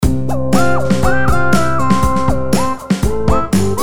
• Качество: 320, Stereo
Electronic
спокойные
без слов
инструментальные
electro